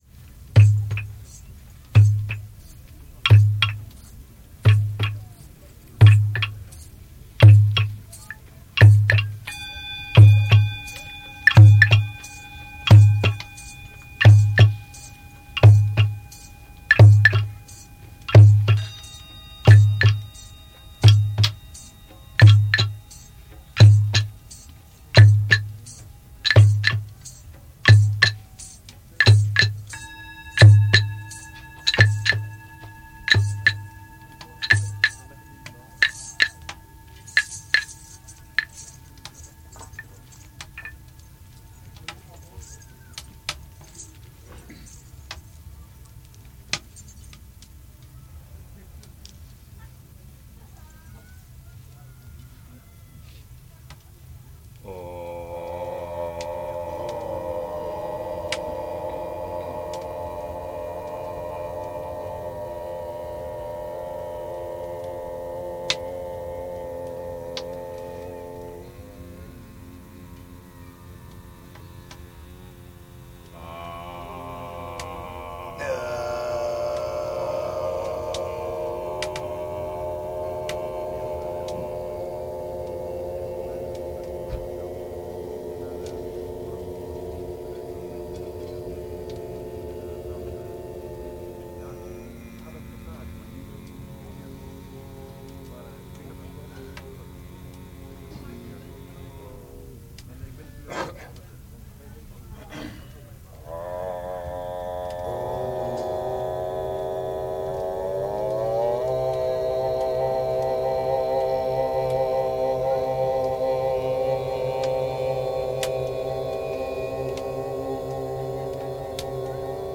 Podcast: opnames Dreamtime Healing Concert en mantra-zingen: Abboneer je op de RSS/Podcast-feed
Opname gemaakt in de Mama-Ger tijdens Landjuweel 2006 in Ruigoord